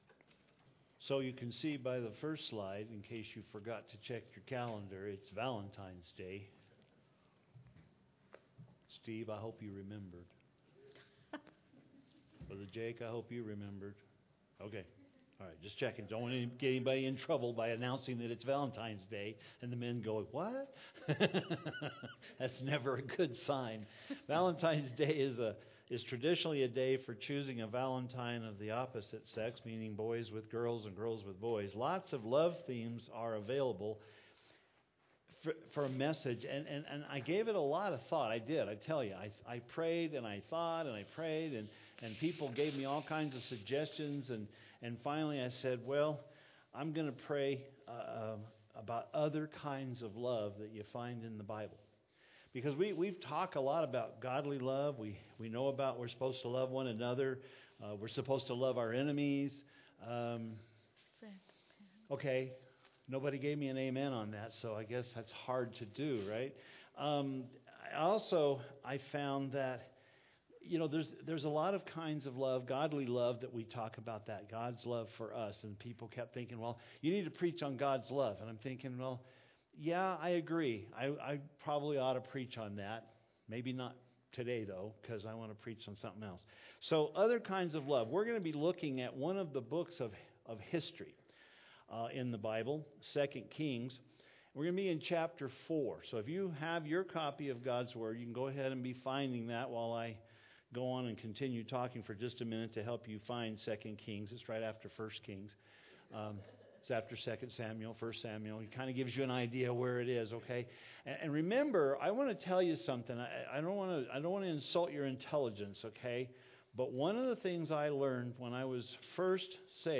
In this world do you feel that love has become complicated? Today's sermon has good news!